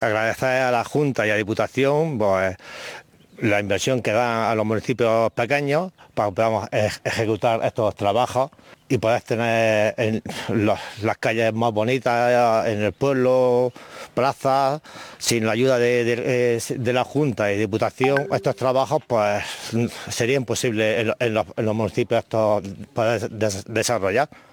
02-03_bayarcal_alcalde.mp3.mp3